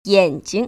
[yăn‧jing] 얜징